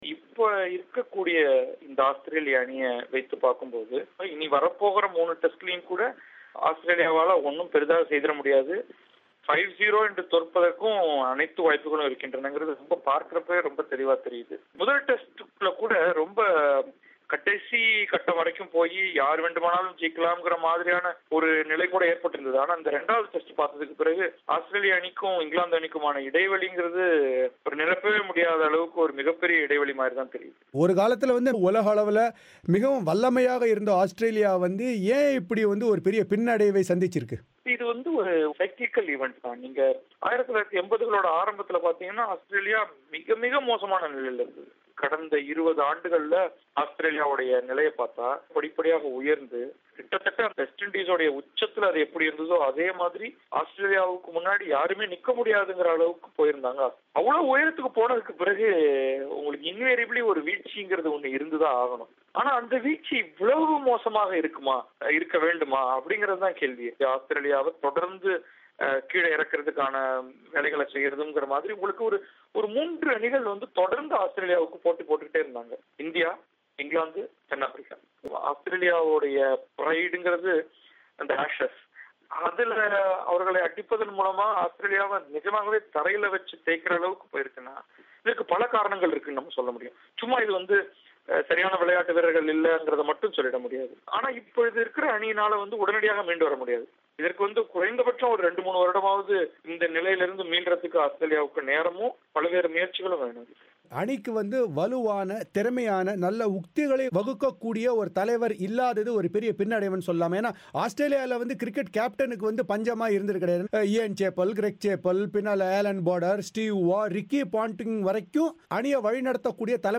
பிபிசி தமிழோசைக்கு அளித்த பேட்டி.